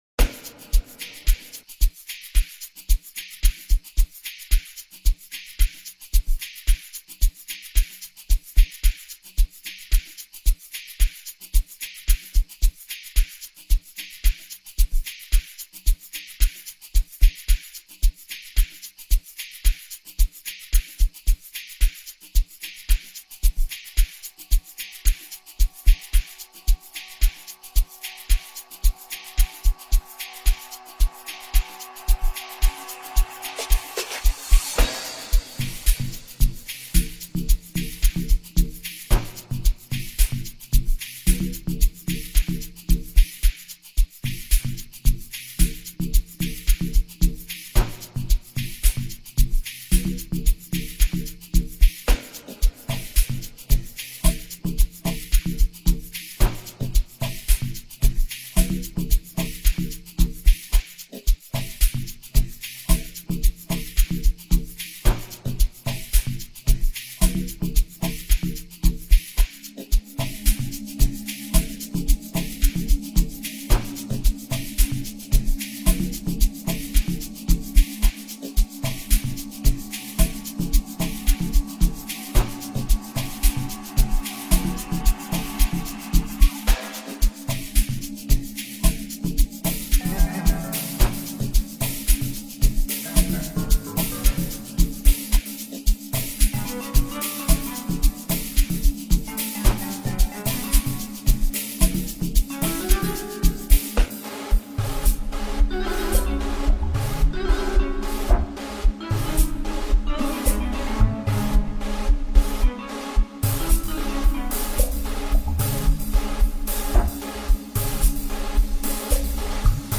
innovative 101 piano sound, a new, refreshing sound
heavy single